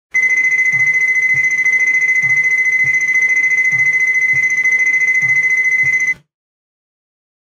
เสียงออเดอร์เข้า Shopee เสียงแจ้งเตือนไลน์แมน
หมวดหมู่: เสียงเรียกเข้า